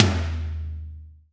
TOM TOM210SL.wav